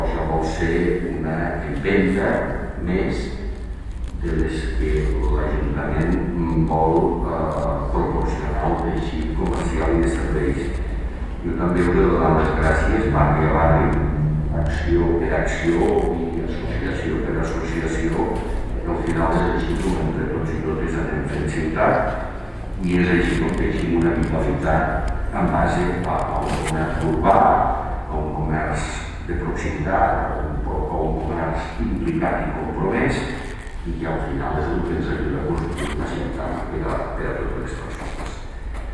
Tall de veu alcalde Pueyo